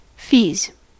Ääntäminen
Ääntäminen US : IPA : [fiːz] Haettu sana löytyi näillä lähdekielillä: englanti Käännöksiä ei löytynyt valitulle kohdekielelle.